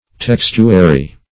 Textuary \Tex"tu*a*ry\, a. [Cf. F. textuaire.]